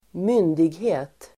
Uttal: [²m'yn:dighe:t]